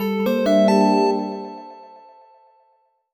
jingle_chime_09_positive.wav